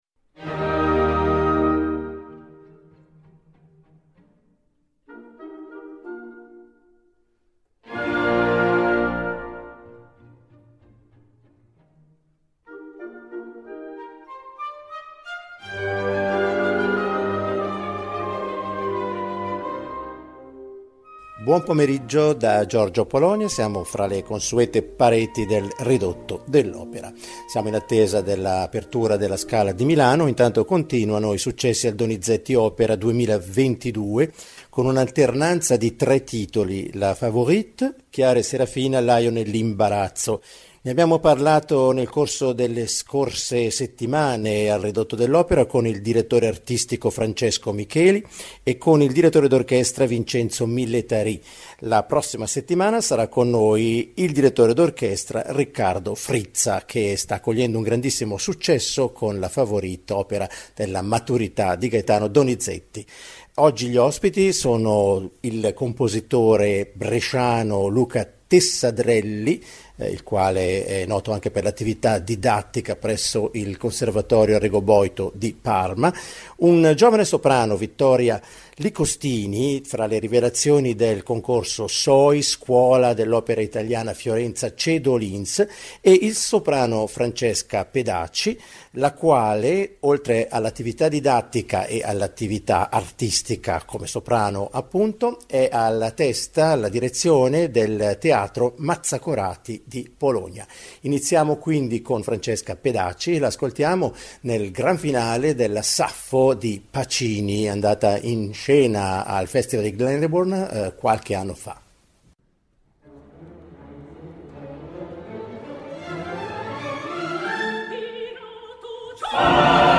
una piacevole chiacchierata